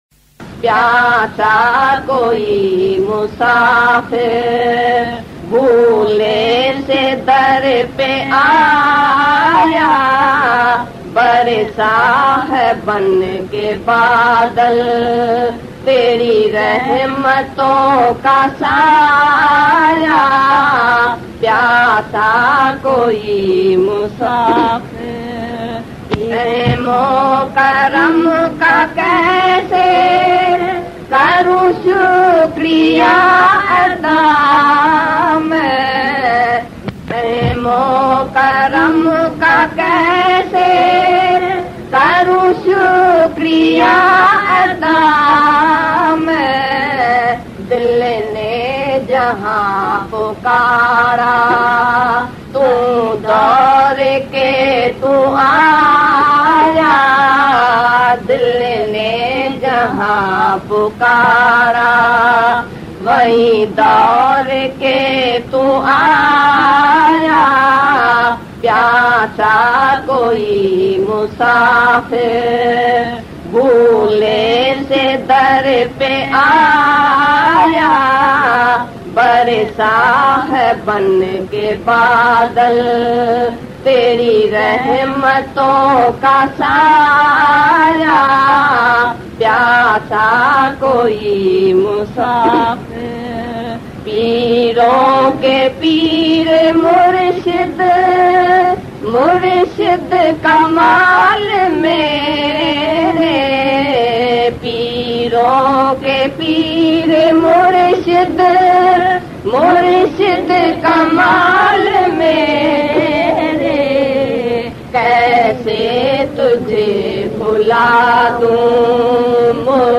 Pyasa-Koi-Musafir-Bhule-Se-Darr-Pe-Aaya-Bhajan.mp3